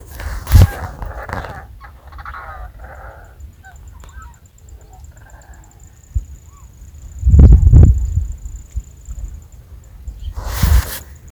Coscoroba (Coscoroba coscoroba)
Nombre en inglés: Coscoroba Swan
Localización detallada: Arroyo las Conchas
Condición: Silvestre
Certeza: Fotografiada, Vocalización Grabada